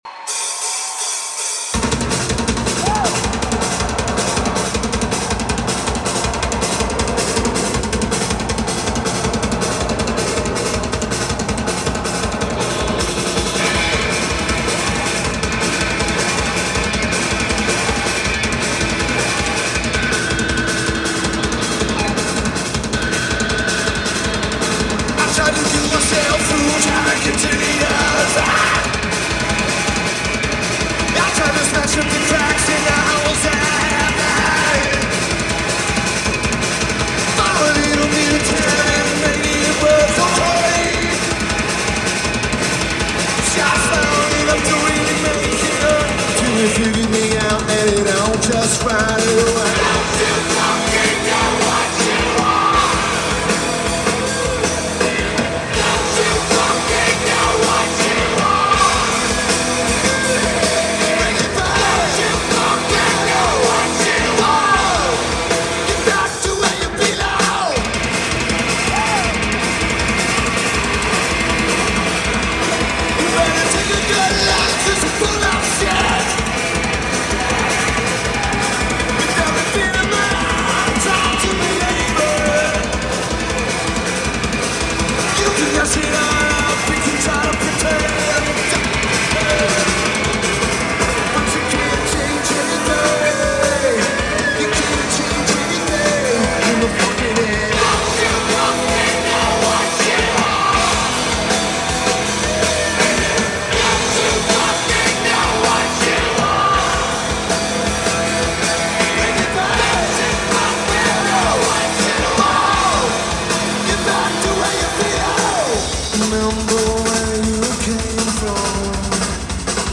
Frank Erwin Center
Lineage: Audio - AUD (Sony ECM-909a + Sony TCD-D8)